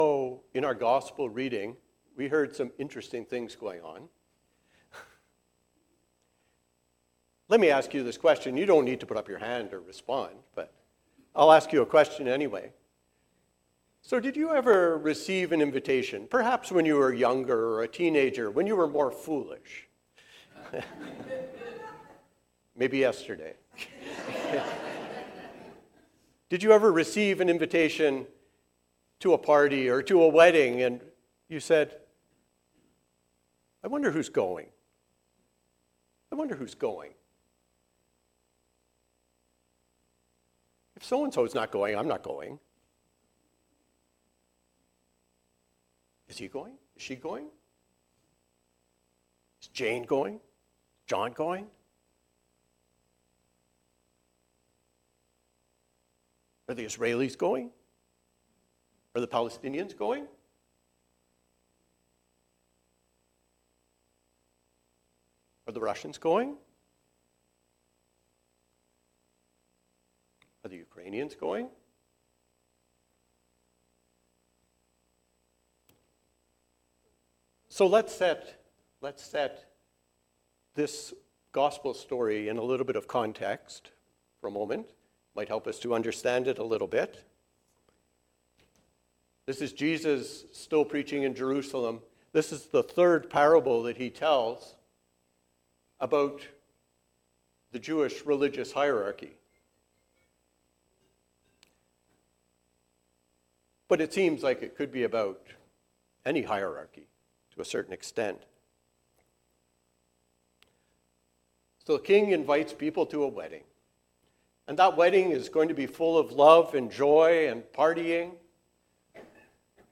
Invited to the Banquet. A sermon on Matthew 22:1-14